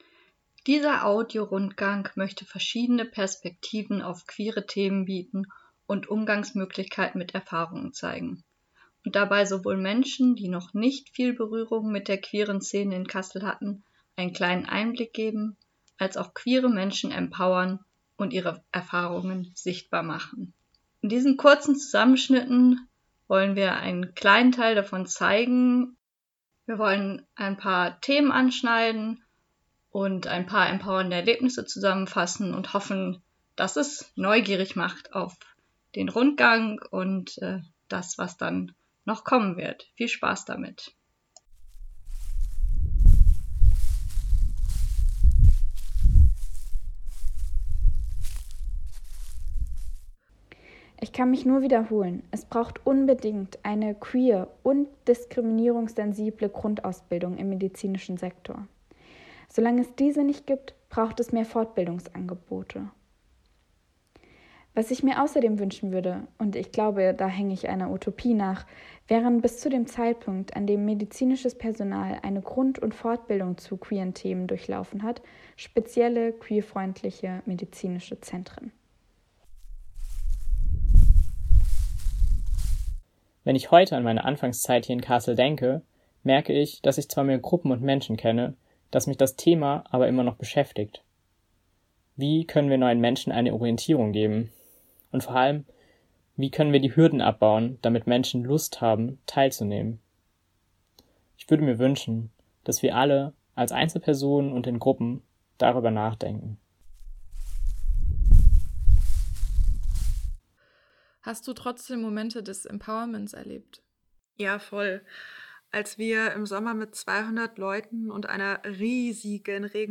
Durch Kassel spazieren und auf dem eigenen Player der Vielfalt queeren Erlebens lauschen: In Form von Geschichten, Interviews, Gedichten oder Musik erzählen queere Personen aus Kassel und Umgebung von ihren Erfahrungen.